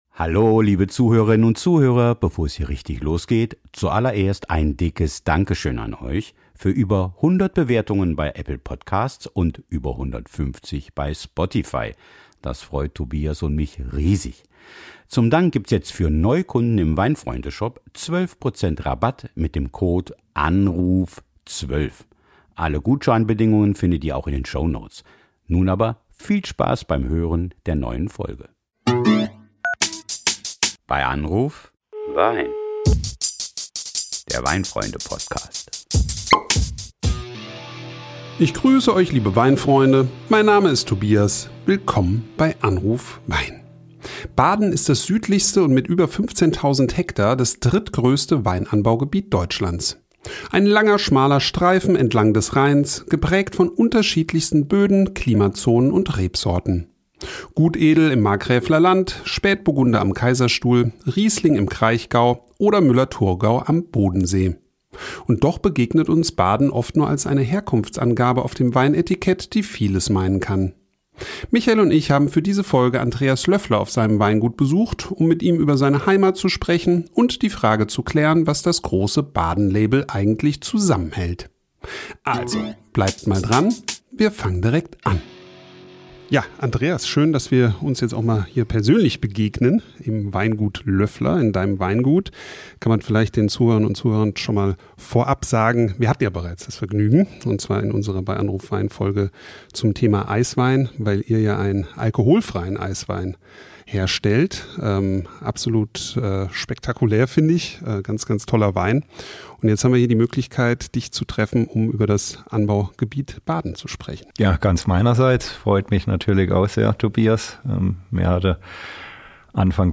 am Telefon